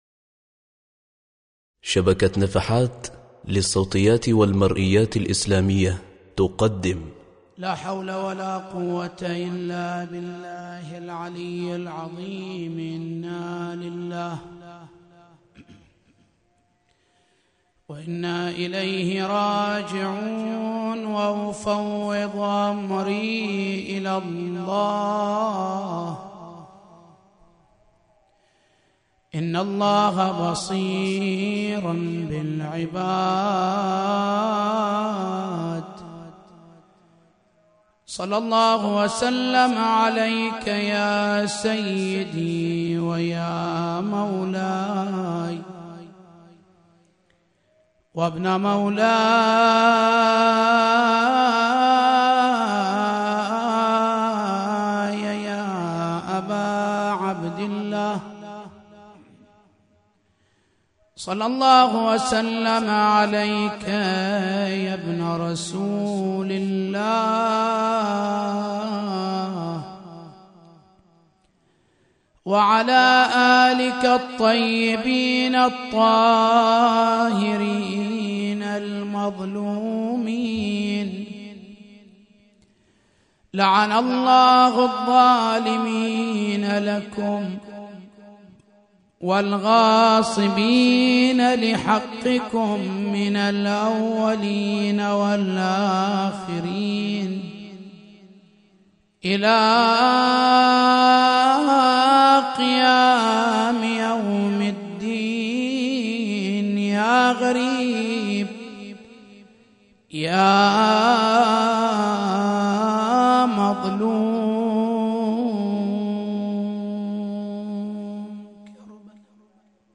مجلس شهادة الامام الرضا ع 1436